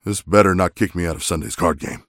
Abrams voice line - This better not kick me out of Sunday's card game.